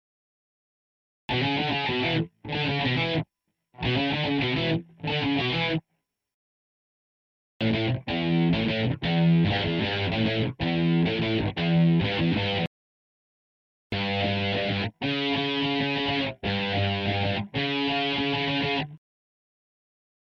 Obbligato
Three obbligatos are sufficient for producing a single piece.